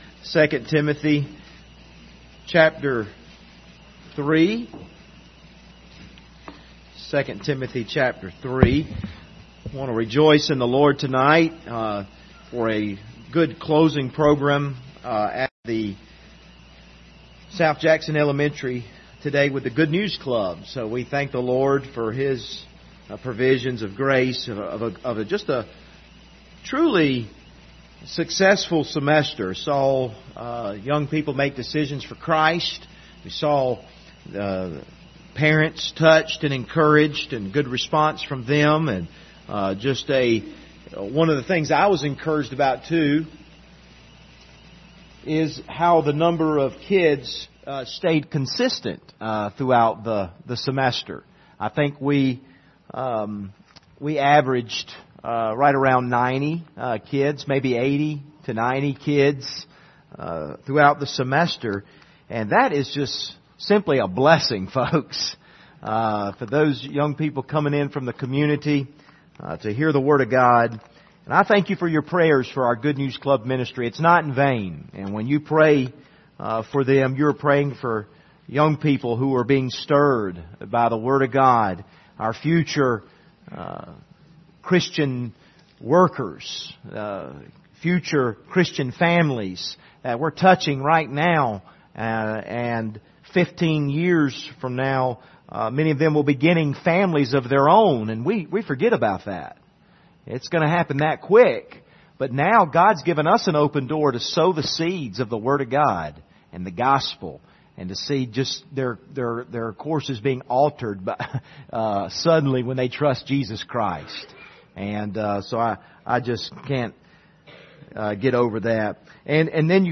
2 Timothy 3:16 Service Type: Wednesday Evening View the video on Facebook Topics